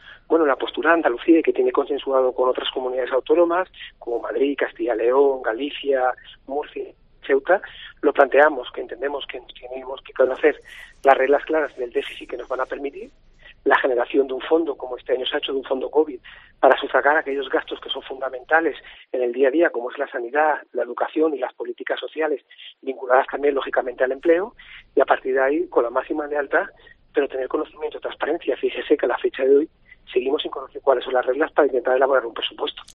El consejero de Hacienda ha valorado en COPE ANDALUCÍA la decisión del gobierno central de suspender las reglas fiscales en 2020 y 2021
Ante la pregunta sobre qué postura adoptará Andalucía en este encuentro, Bravo ha contestado tajante en una entrevista concedida a COPE ANDALUCÍA: "¿Alguien piensa que le irá bien a España si no le va bien a Andalucía? Somos el 18% de la población nacional. No ir de la mano sería un grave error."